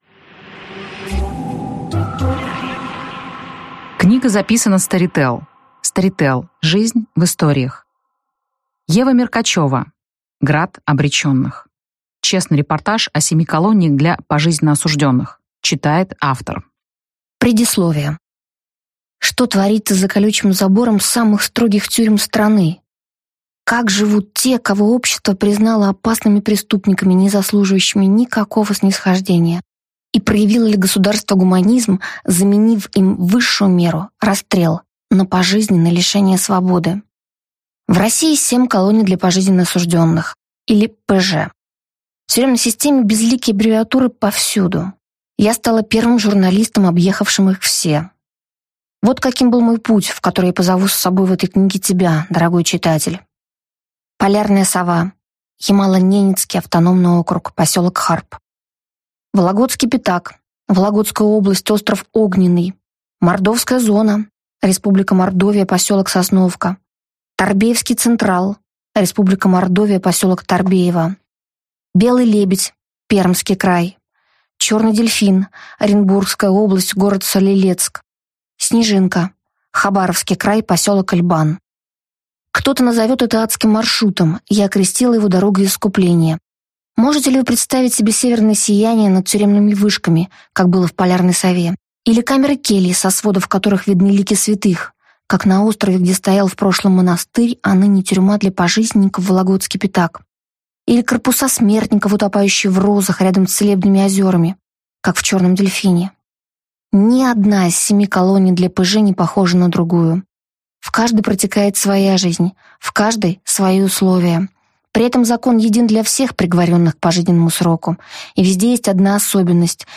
Аудиокнига Град обреченных. Честный репортаж о семи колониях для пожизненно осуждённых | Библиотека аудиокниг